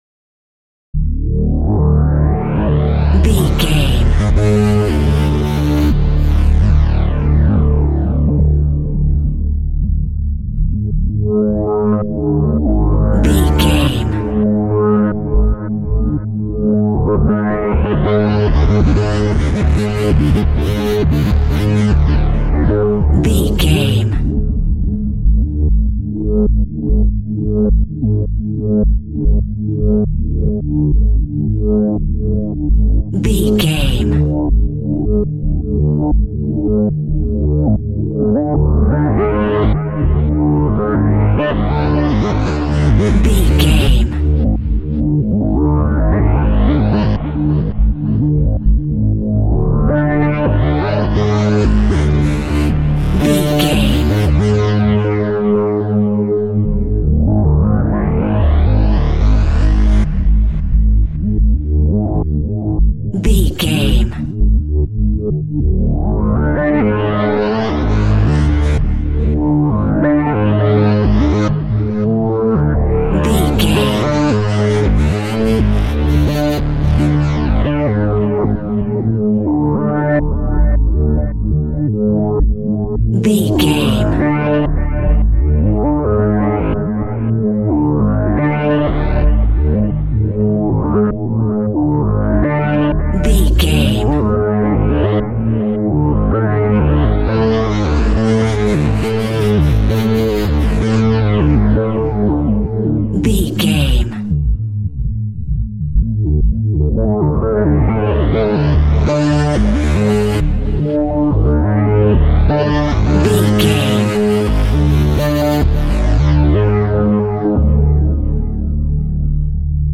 Horror Movie Soundtrack.
Lydian
D
tension
ominous
haunting
eerie
driving
synthesiser
Horror synth
Horror Ambience